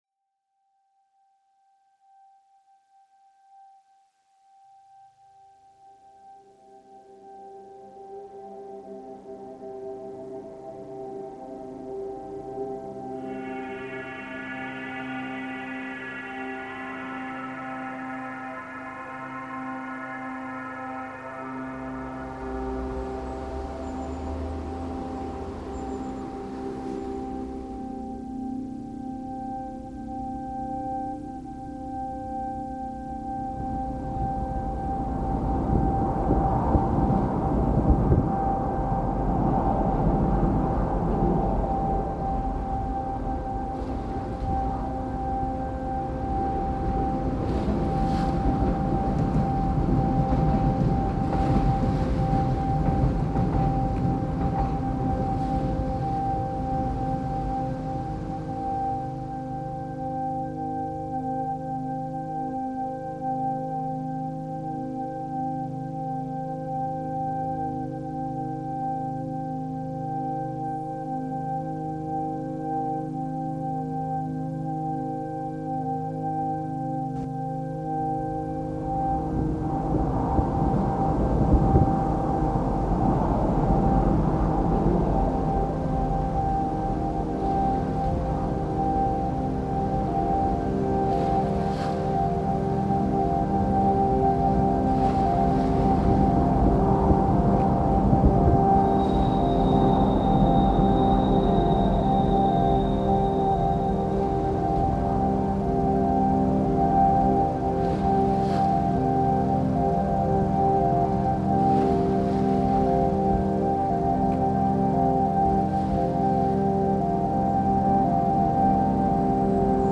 Forth Bridge reimagined